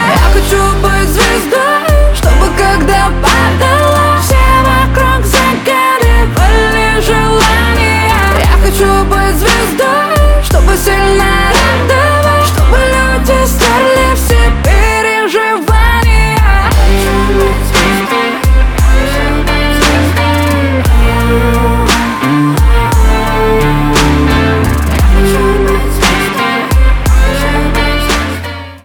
поп
гитара